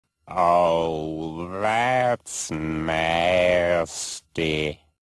Fart Meme Sound
oh-thats-nasty-cleveland.mp3